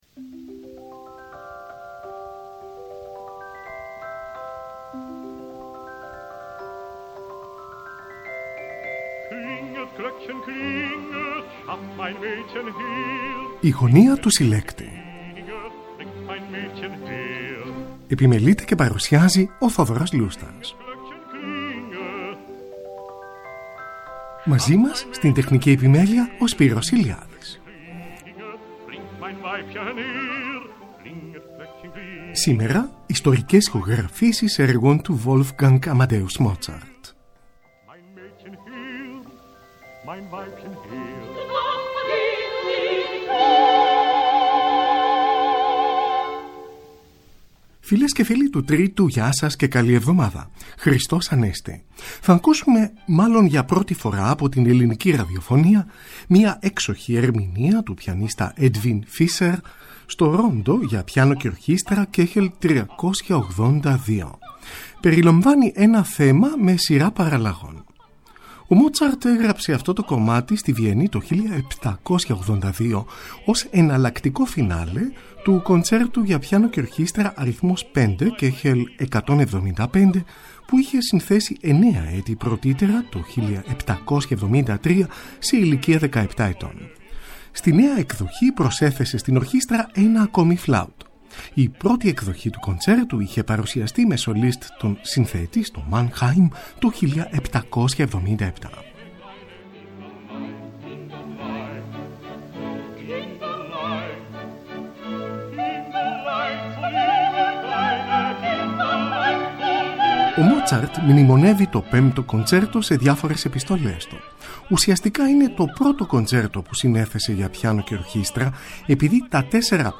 ΙΣΤΟΡΙΚΕΣ ΗΧΟΓΡΑΦΗΣΕΙΣ ΕΡΓΩΝ ΤΟΥ WOLFGANG AMADEUS MOZART
Rondo για πιάνο και ορχήστρα, Κ.382. Τον πιανίστα Edwin Fischer συνοδεύει η Ορχήστρα της Βαυαρικής Ραδιοφωνίας υπό τον Eugen Jochum. 26 Ιουνίου 1954. Ηχογράφηση της Βαυαρικής Ραδιοφωνίας στο Μόναχο, άγνωστη και ανέκδοτη, ως το 2011.